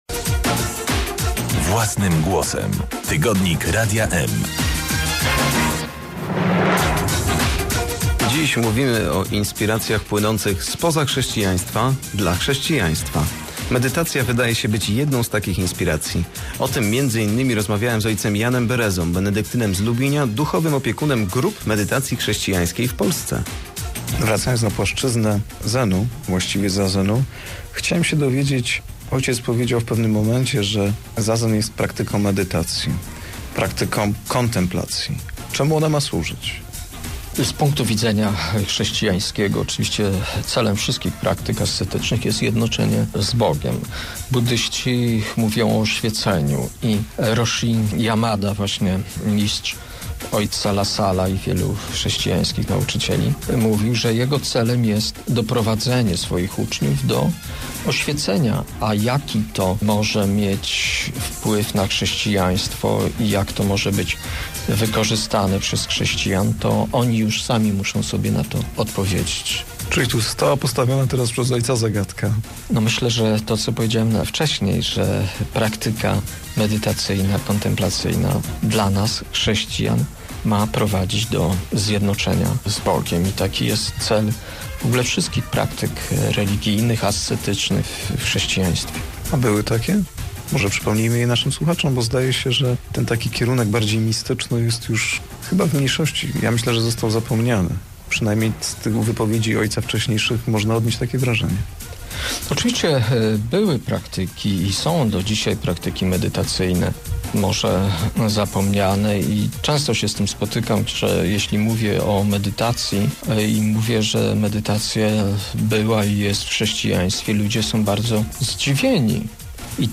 Wywiad dla Radia eM